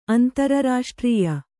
♪ antararāṣtrīya